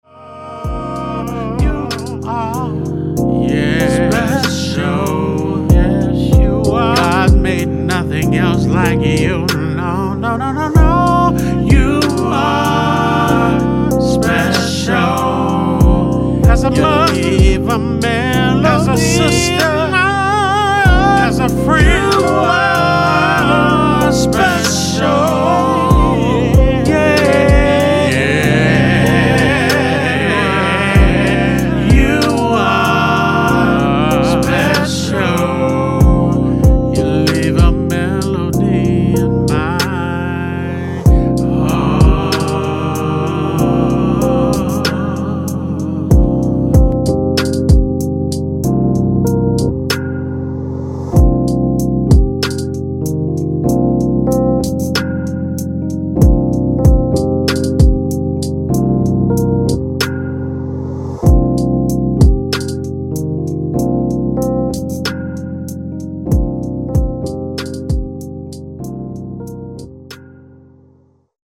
RHYTHMIC SOUL